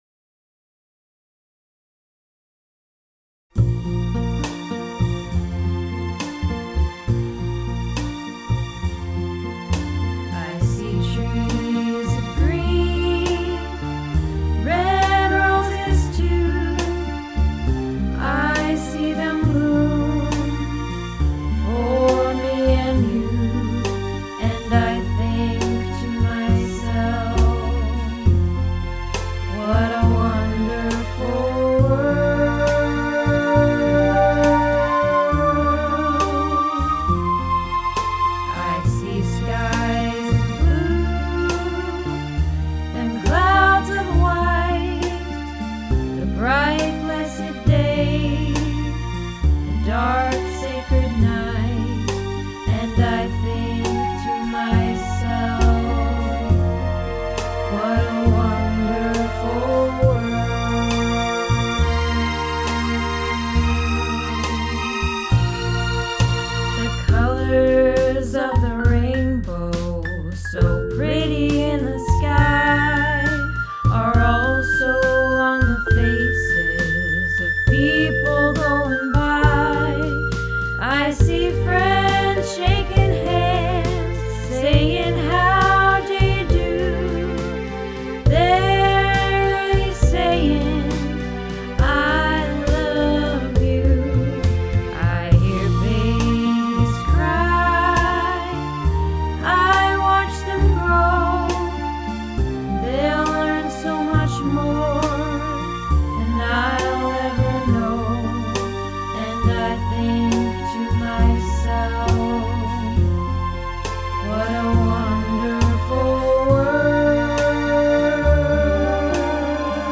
What a Wonderful World - Alto